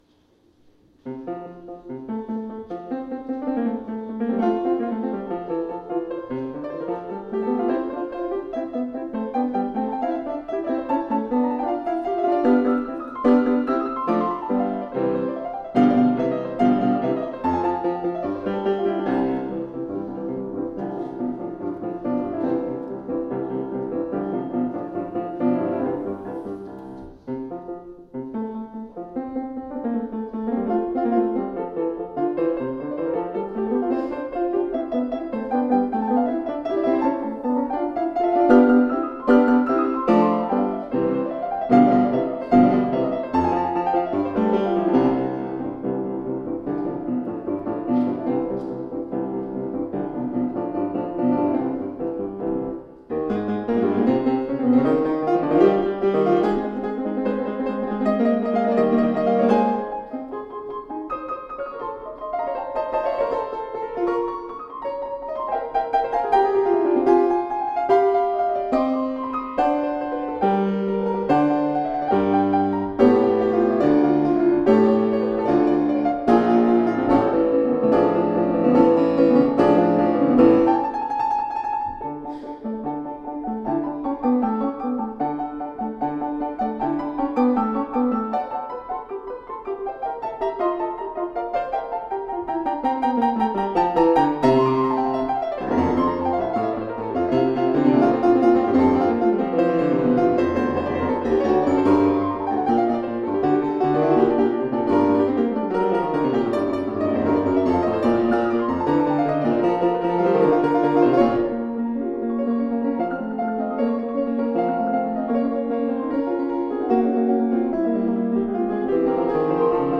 theme, which is treated contrapuntally in the manner of a fugue.
Subject: Ludwig van Beethoven - Piano Sonata